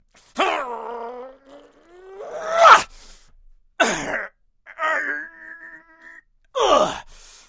Звуки напряжения от тяжести
Звук мужчины поднимающего большой камень с напряжением в голосе